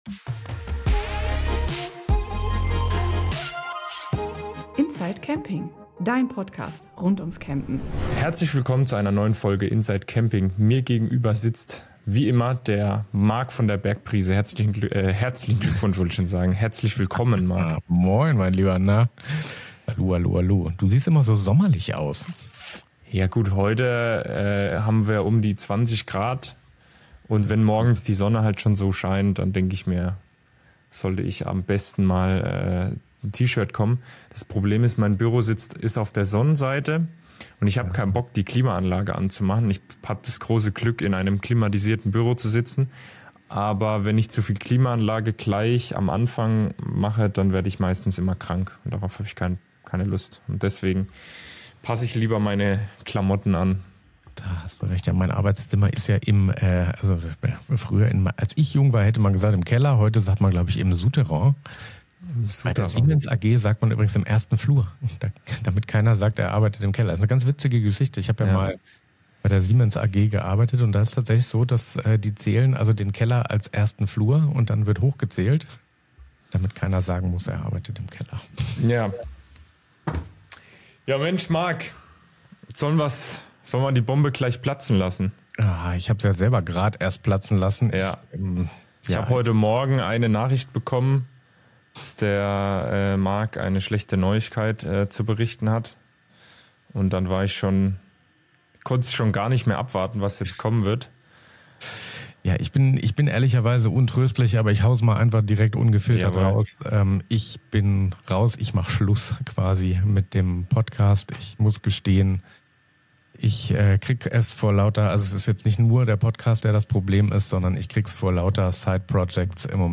meldet sich von Terminal 2 im Frankfurter Flughafen